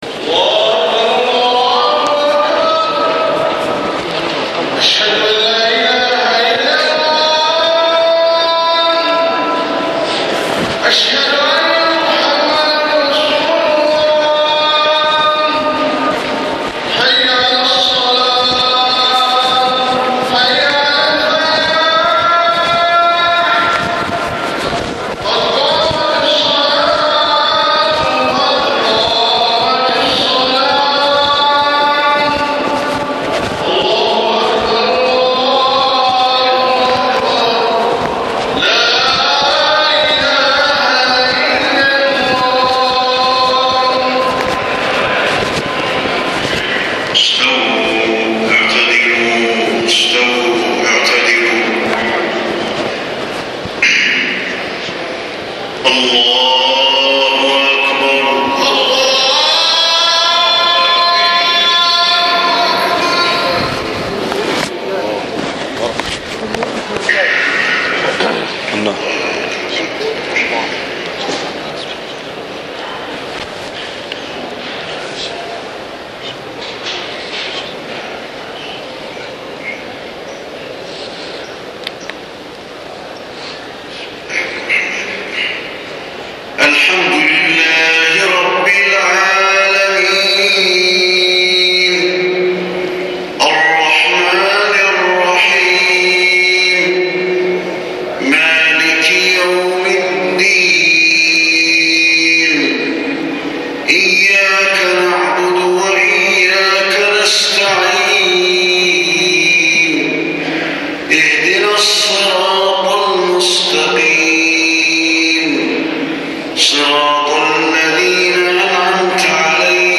صلاة العشاء 1 صفر 1431هـ خواتيم سورة لقمان 26-34 > 1431 🕌 > الفروض - تلاوات الحرمين